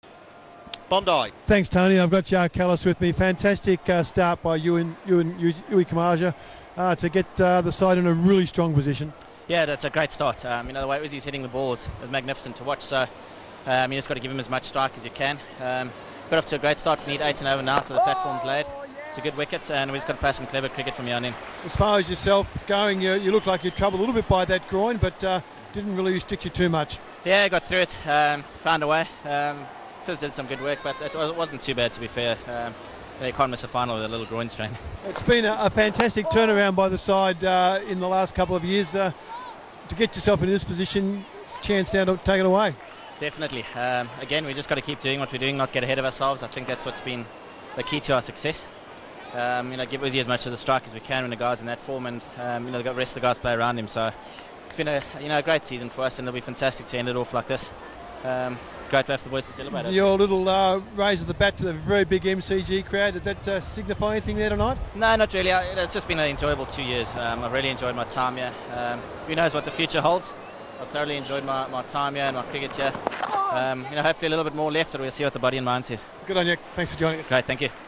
INTERVIEW: Jacques Kallis speaks after his innings for Sydney Thunder in the BBL05 Final.